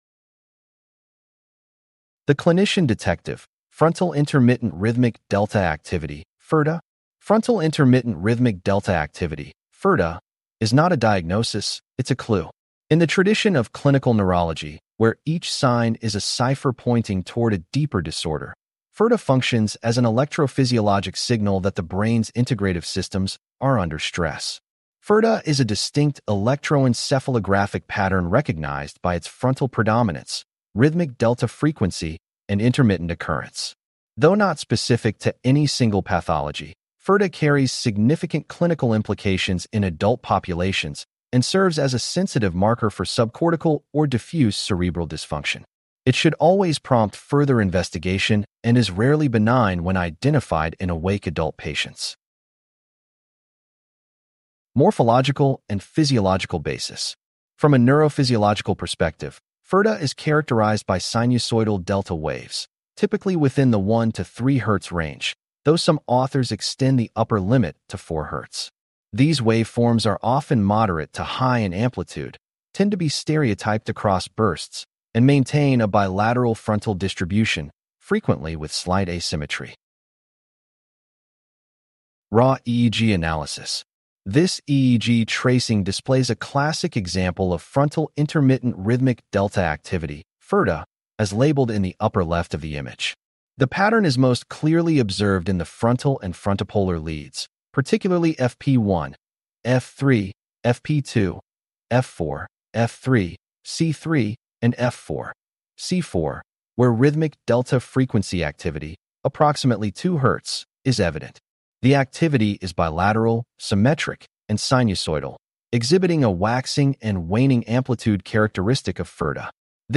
CLICK TO HEAR THIS POST NARRATED FIRDA is a distinct electroencephalographic pattern recognized by its frontal predominance, rhythmic delta frequency, and intermittent occurrence.